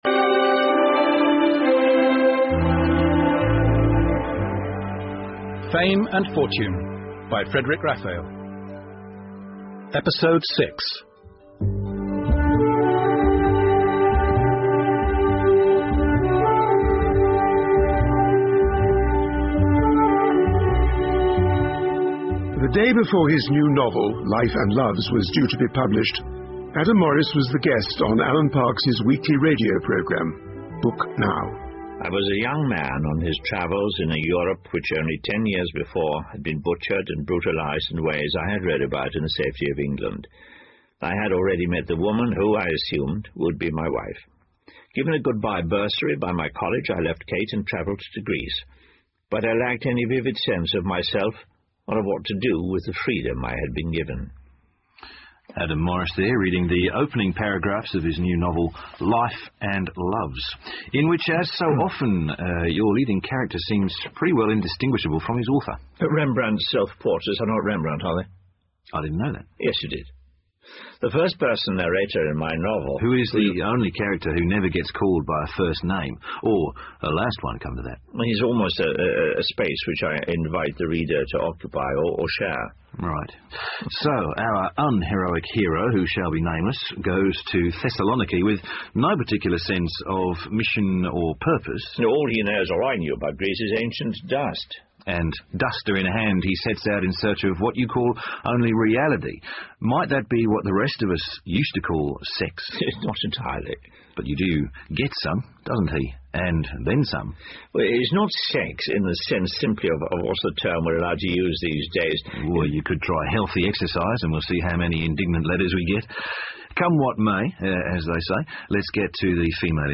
英文广播剧在线听 Fame and Fortune - 56 听力文件下载—在线英语听力室